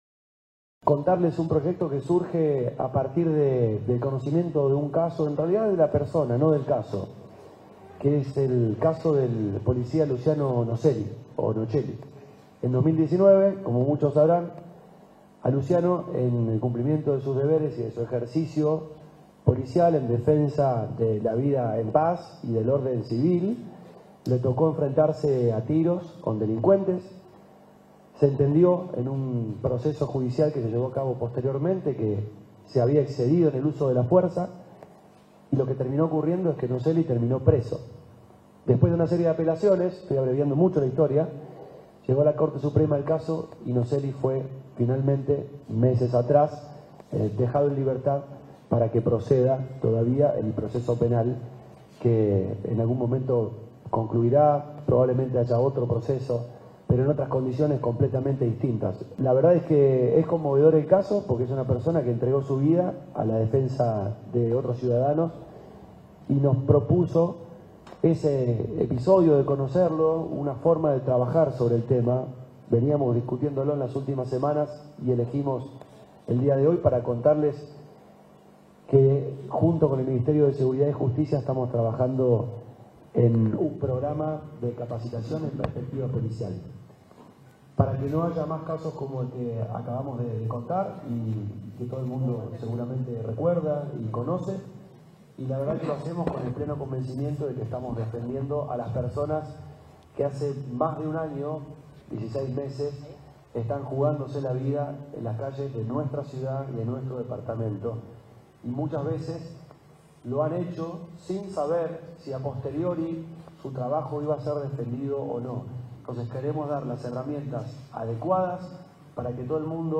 El ministro Coccocioni y el senador Seisas brindaron detalles sobre el proyecto.
Fragmentos de las declaraciones de Seisas y Cococcioni